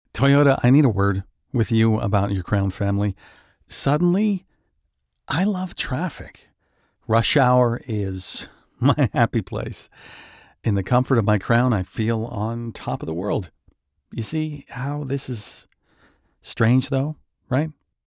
Commercial
Male
20s, 30s, 40s, 50s
American English (Native)
Bright, Confident, Corporate, Friendly, Natural
I have a midrange neutral North American voice. My voice is youthful but versatile, and I can ably perform a range of tones and inflections from fun young adult to Old English storyteller.
Microphone: Sennheiser MKH 416 & Neumann TLM 103